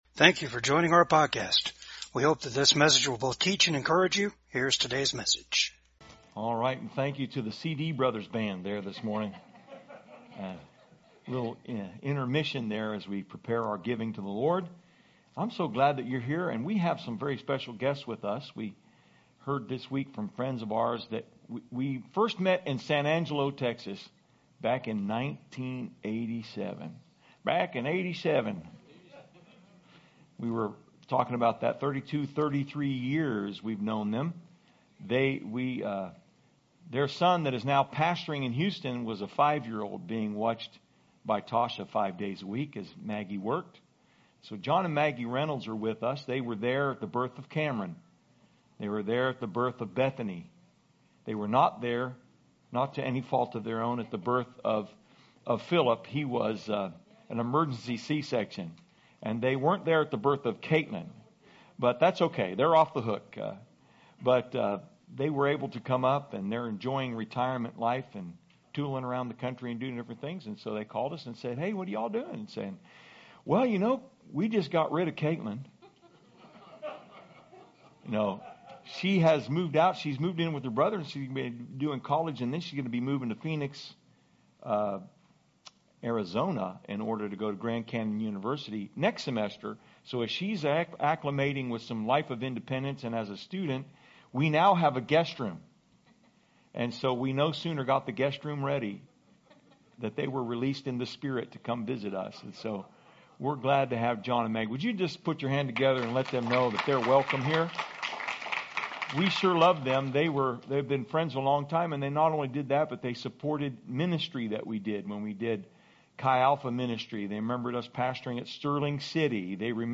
Passage: Jeremiah 23:29-30 Service Type: VCAG SUNDAY SERVICE